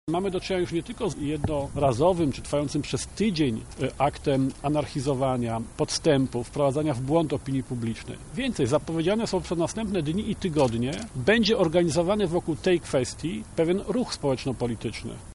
O działaniu polityków PIS i SLD mówi przewodniczący Twojego Ruchu, Janusz Palikot: